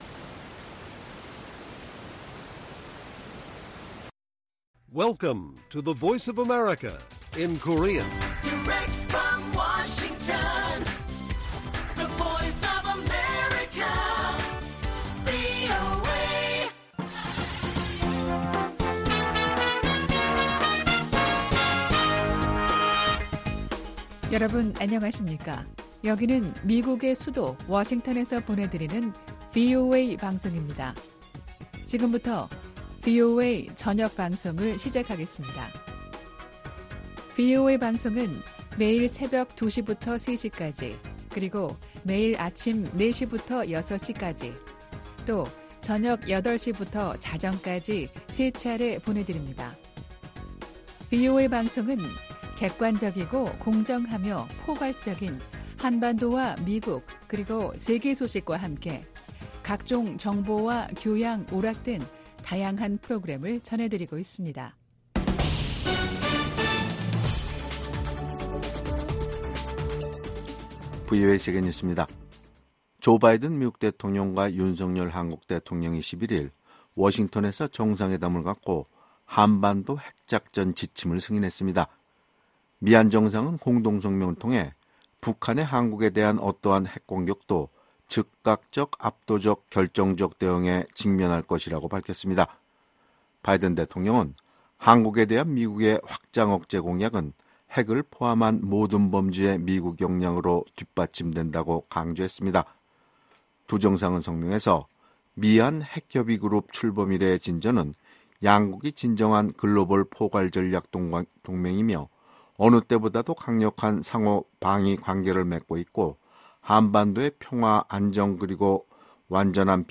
VOA 한국어 간판 뉴스 프로그램 '뉴스 투데이', 2024년 7월 12일 1부 방송입니다. 조바이든 미국 대통령과 윤석렬 한국 대통령은 워싱턴에서 북한의 한국 핵 공격 시, 즉각적이고 압도적이며 결정적인 대응을 한다는 공동성명을 발표했습니다.